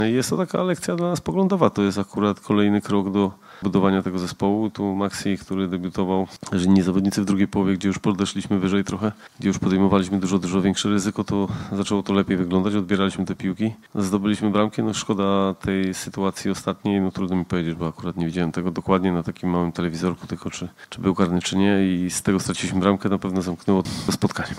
podsumował – Michał Probierz